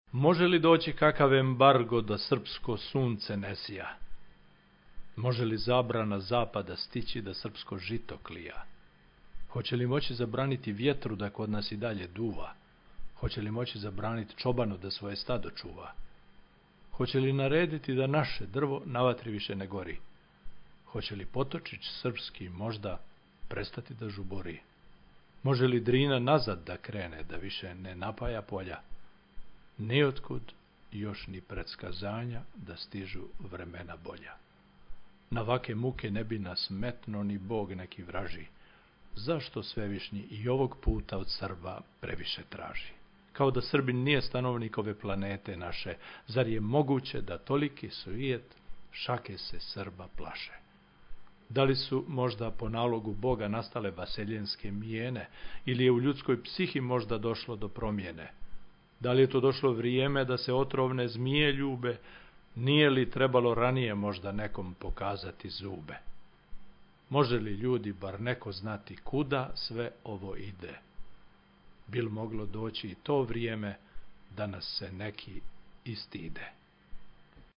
Pesmu kazuje